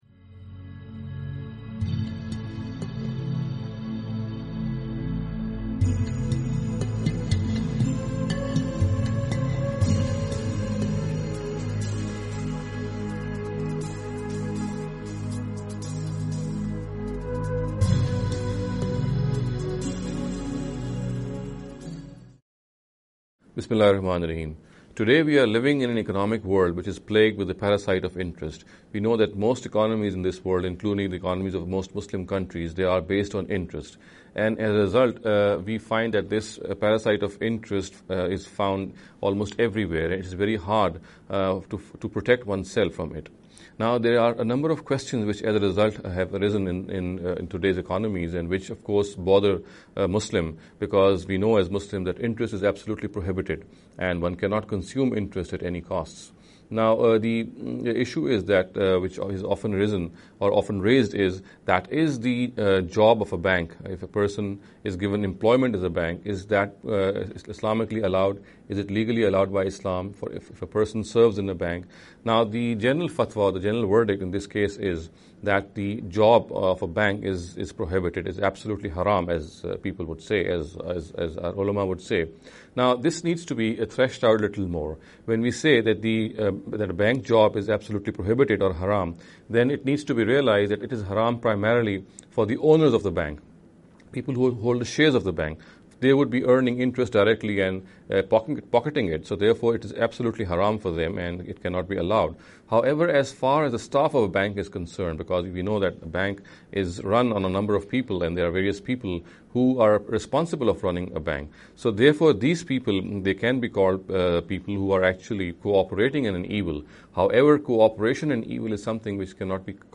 This lecture series will deal with some misconception regarding the Economic Directives of Islam.